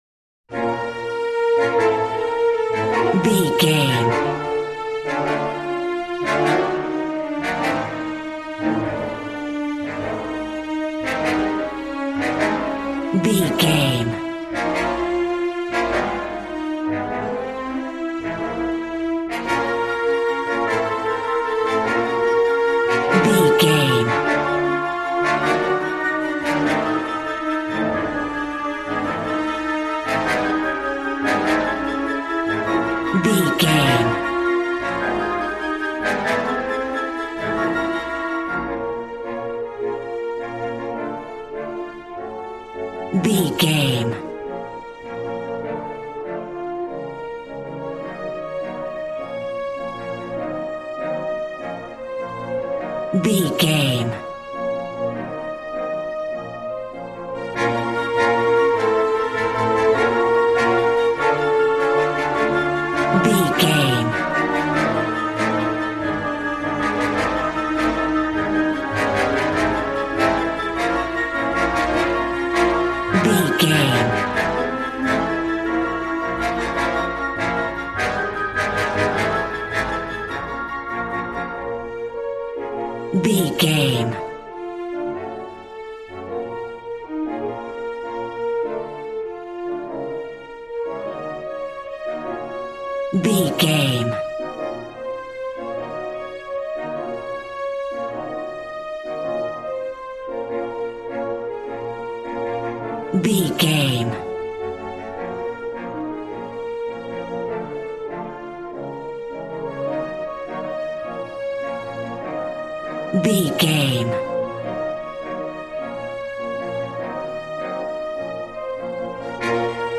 Ionian/Major
G♭
dramatic
powerful
epic
percussion
violin
cello